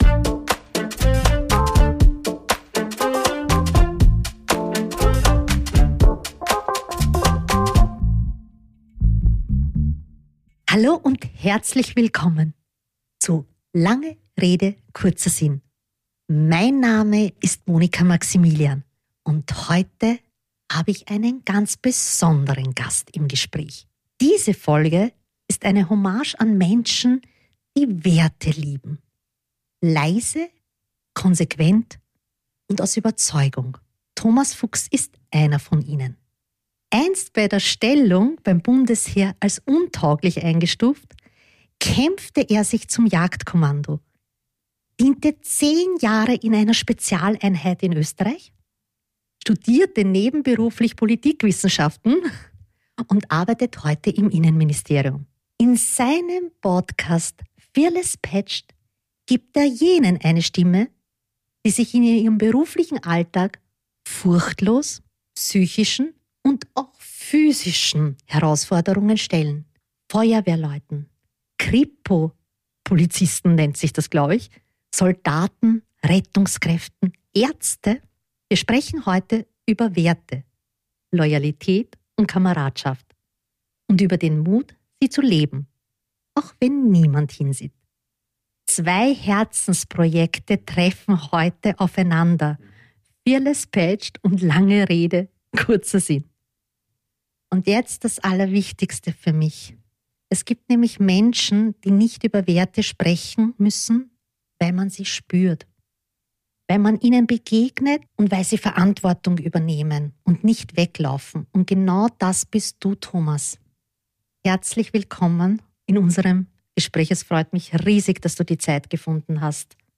Ein Gespräch, das inspiriert.